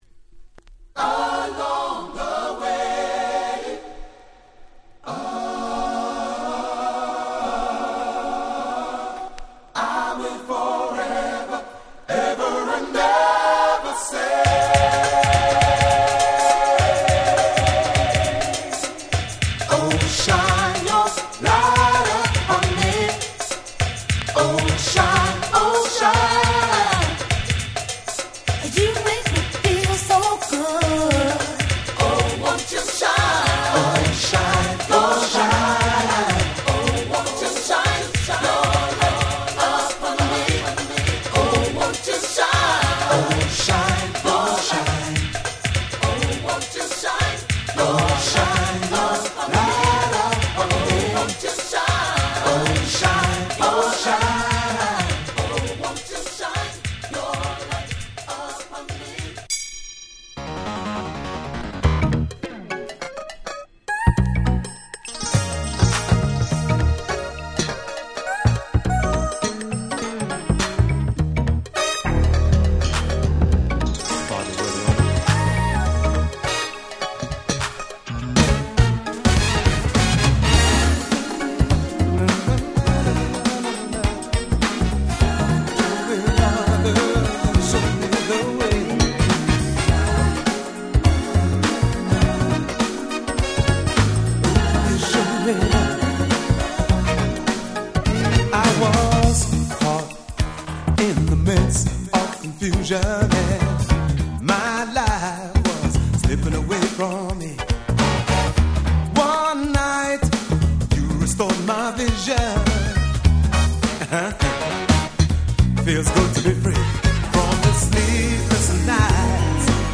92' Nice UK R&B LP !!